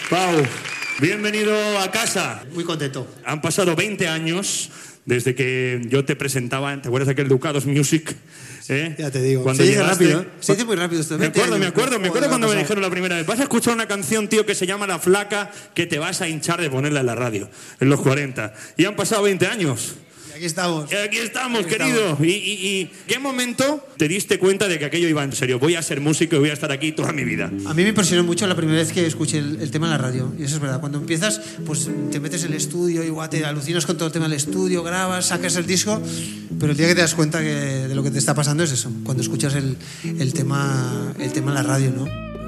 Fragment d'una entrevista a Pau Donés del grup "Jarabe de Palo".
Musical
FM